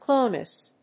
/ˈkləʊnəs(米国英語)/